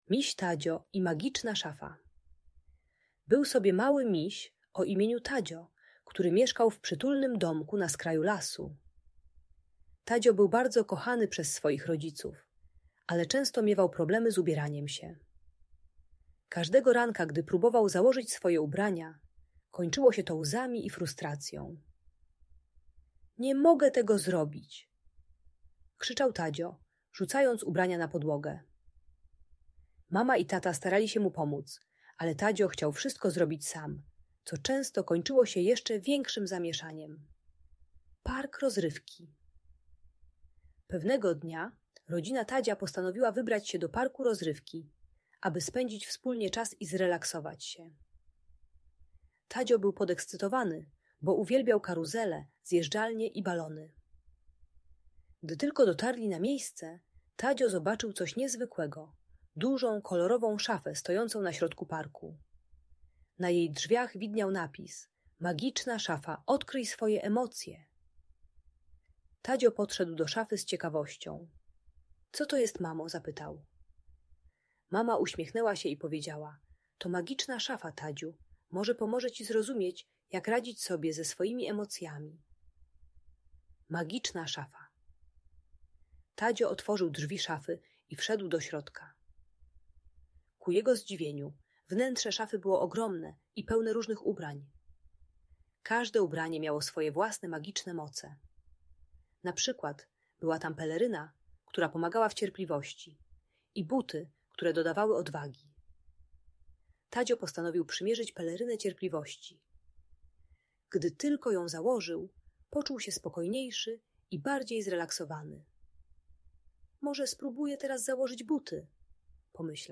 Historia Misia Tadzia i Magicznej Szafy - Audiobajka